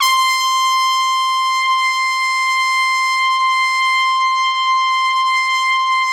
Index of /90_sSampleCDs/Best Service ProSamples vol.20 - Orchestral Brass [AKAI] 1CD/Partition A/VOLUME 002